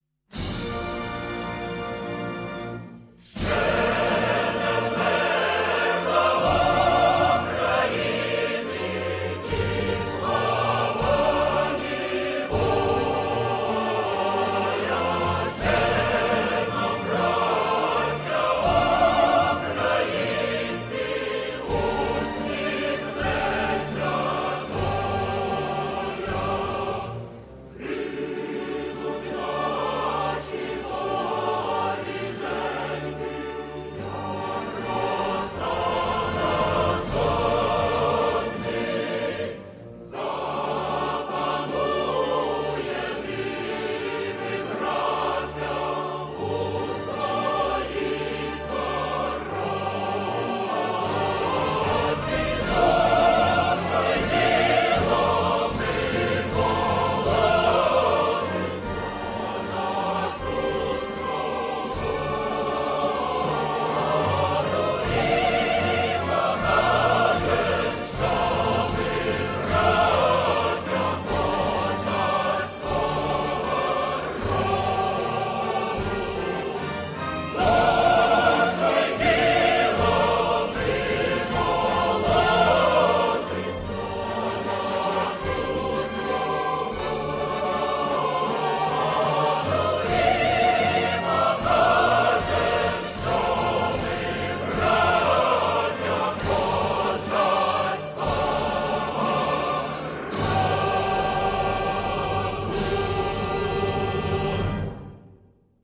Торжественная мелодия и слова патриотической песни \
Инструментальная музыка